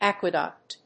aquaduct.mp3